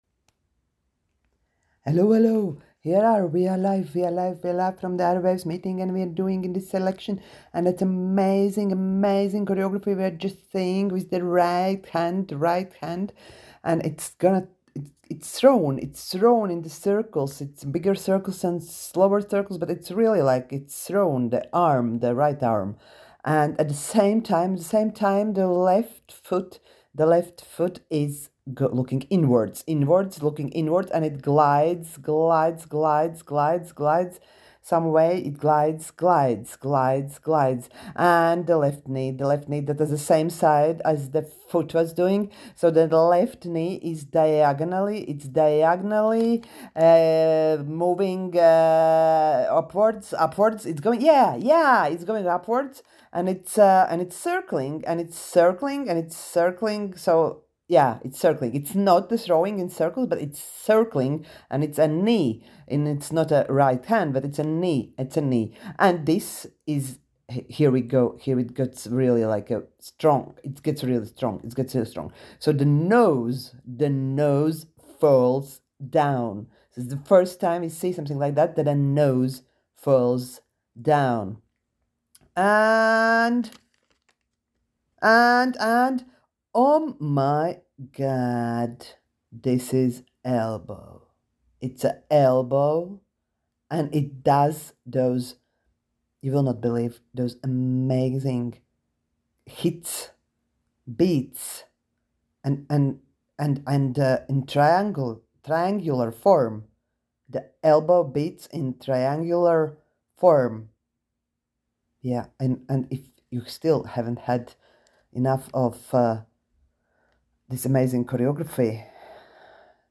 en I am using a list of instructions made as part of one of the collective exercises to produce audio dance experience. I am refering to sports programmes where fast and excited speech is a tool to generate excitement.